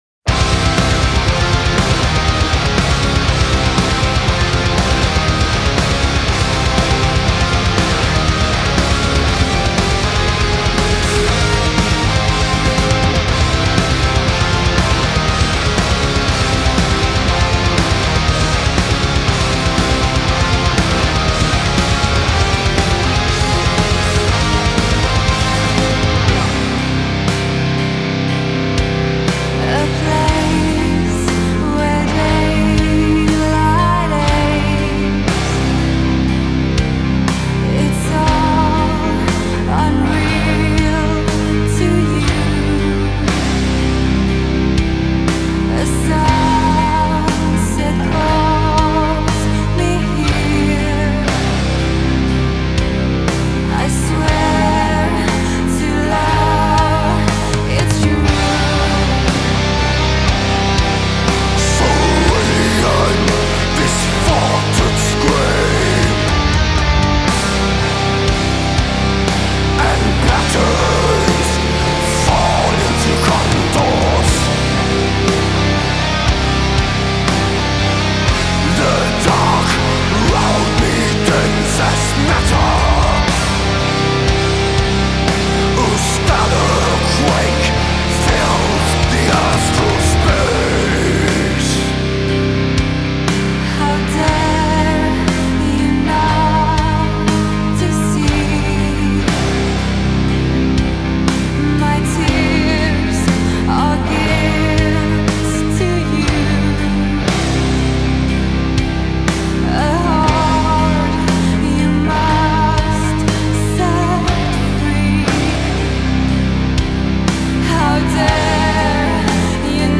سبک موسیقی متال